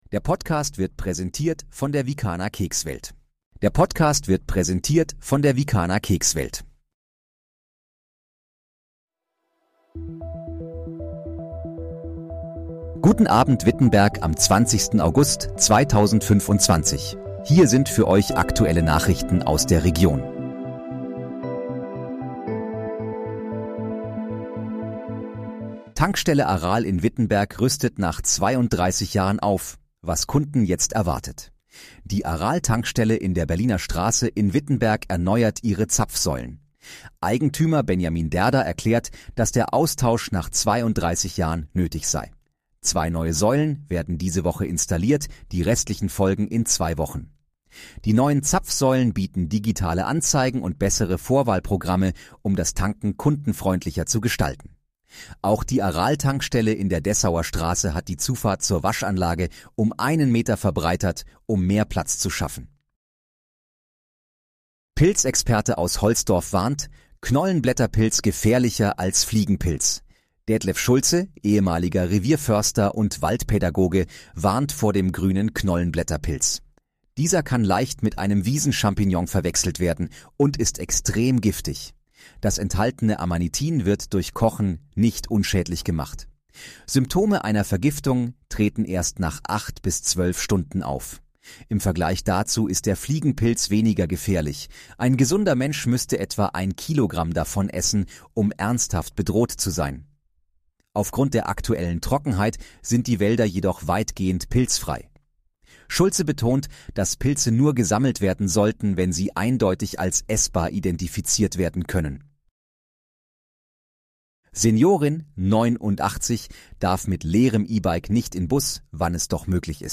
Guten Abend, Wittenberg: Aktuelle Nachrichten vom 20.08.2025, erstellt mit KI-Unterstützung